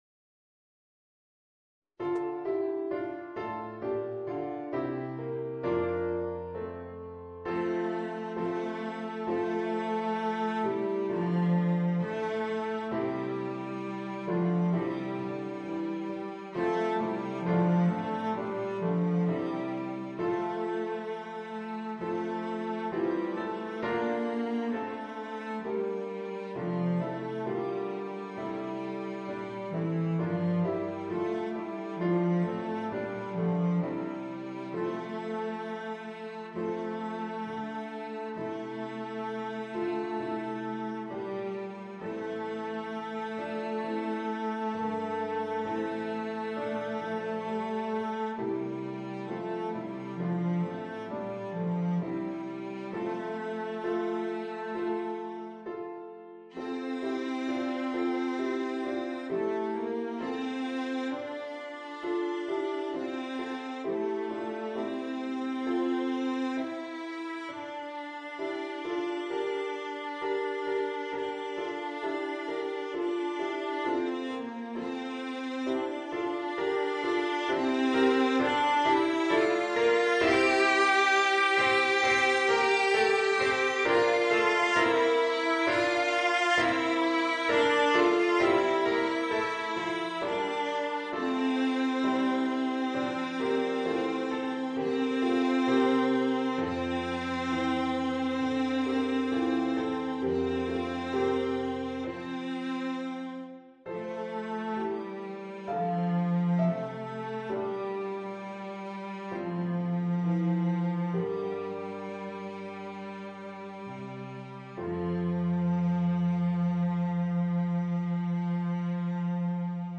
Violoncello & Klavier